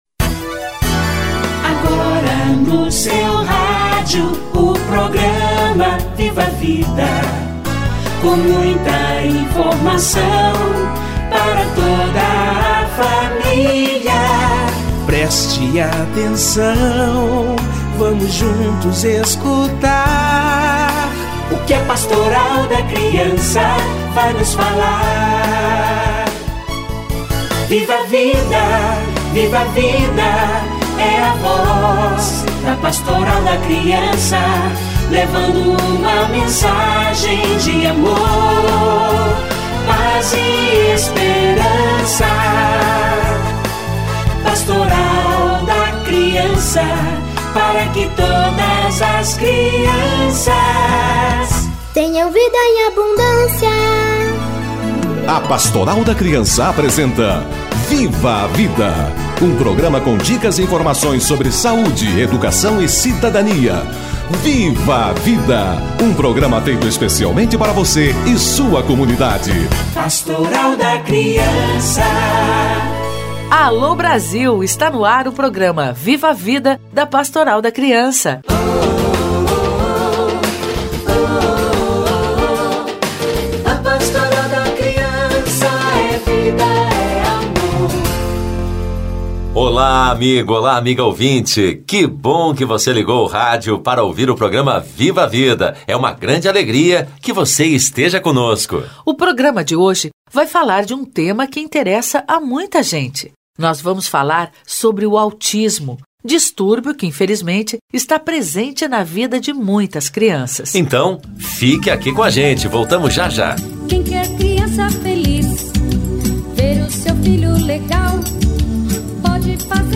Autismo e aleitamento materno - Entrevista